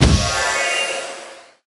ninja_invis_01.ogg